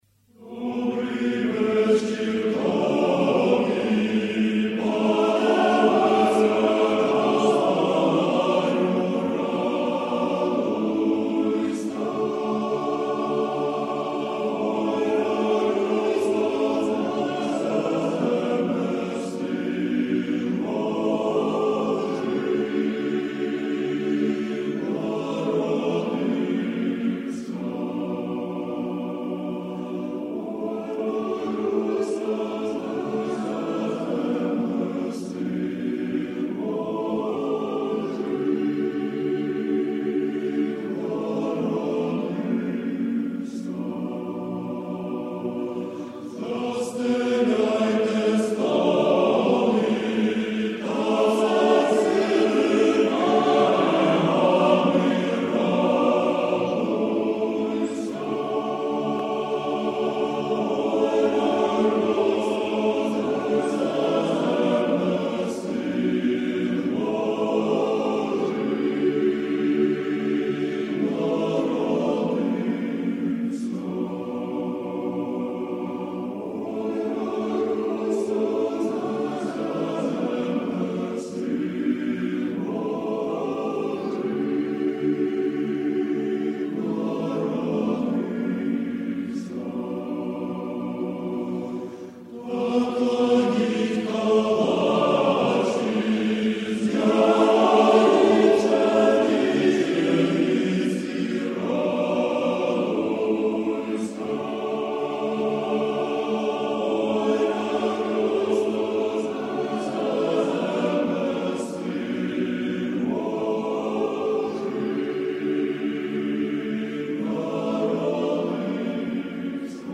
Українська колядка